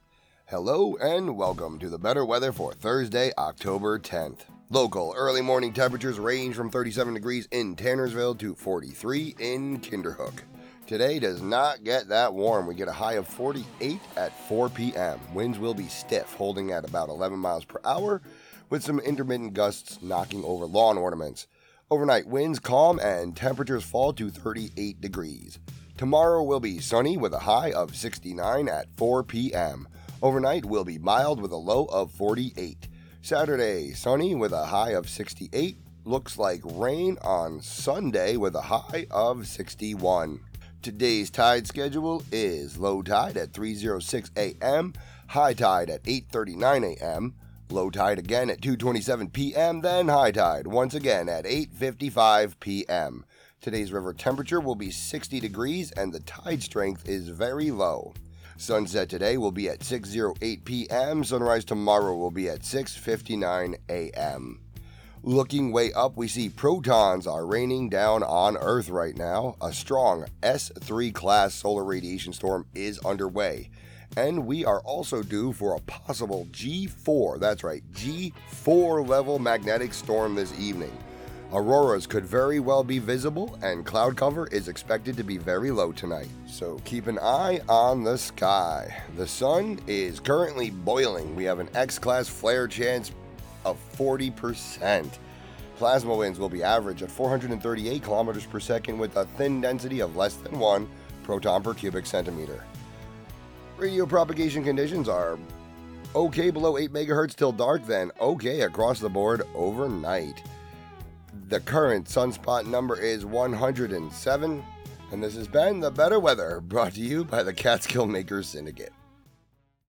broadcasts